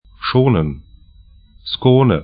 Schonen 'ʃo:nə